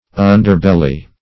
Underbelly \Un"der*bel`ly\, n.